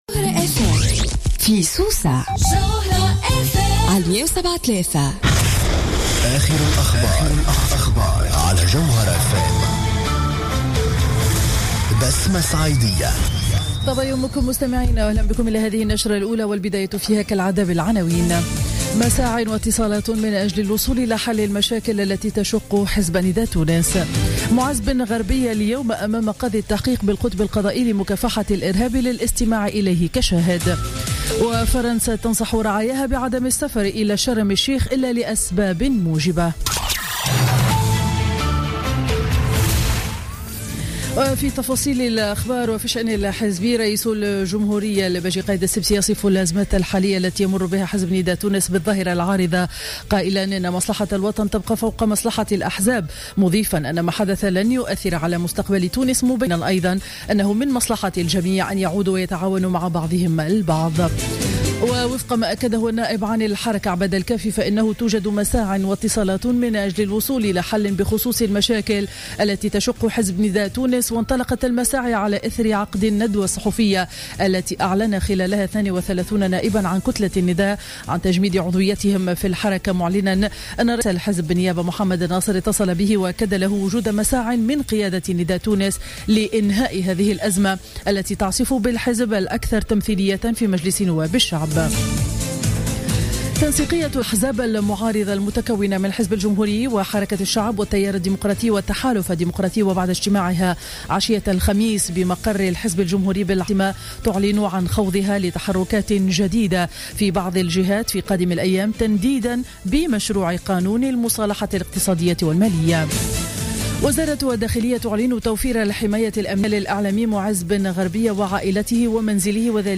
نشرة أخبار السابعة صباحا ليوم الجمعة 6 نوفمبر 2015